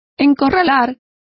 Complete with pronunciation of the translation of corral.